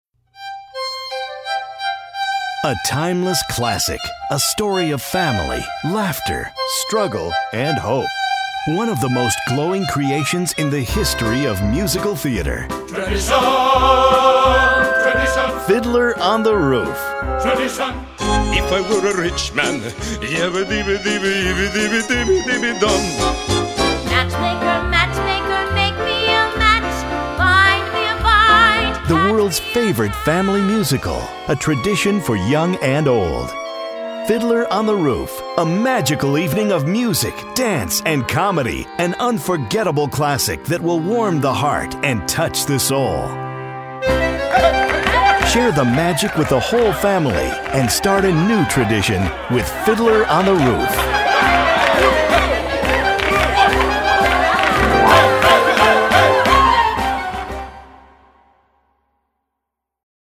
Fiddler on the Roof Radio Commercial